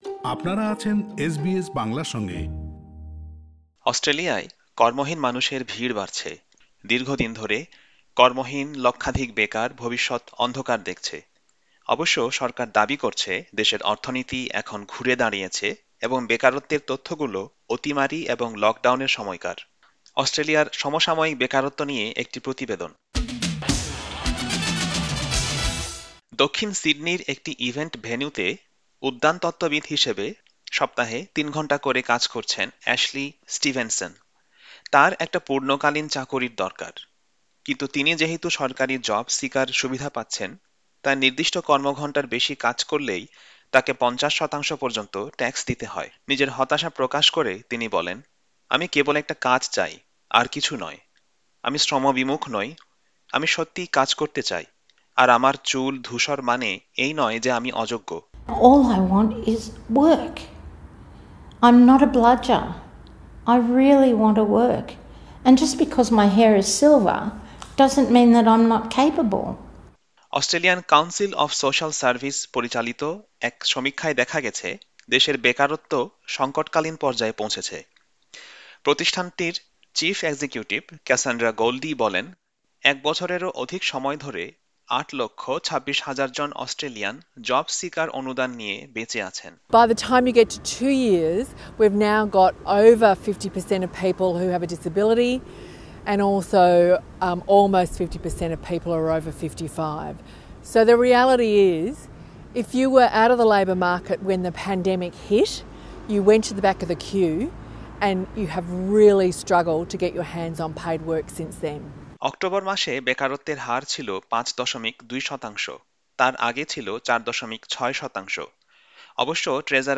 অস্ট্রেলিয়ায় সমসাময়িক বেকারত্ব নিয়ে একটি প্রতিবেদন।